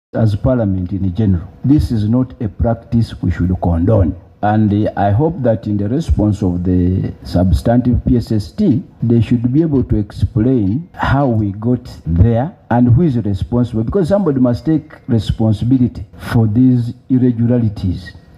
In a meeting held on Monday, 23 February 2026, a team from the ministry led by the Deputy Permanent Secretary and Secretary to the Treasury, Patrick Ocailap  appeared before the committee to respond to queries in the final audit report for the financial year 2024/2025.